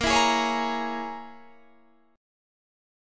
Bbm7b5 chord